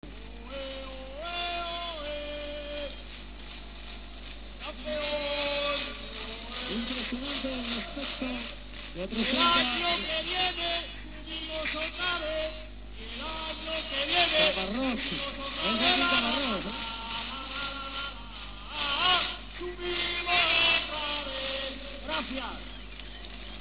Celebración del ascenso a segunda división.